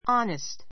h onest ɑ́nist ア ネ スト （ ⦣ h は発音しない） 形容詞 正直な, 誠実な an honest boy an honest boy 正直な少年 an honest opinion an honest opinion 率直 そっちょく な意見 He is very honest.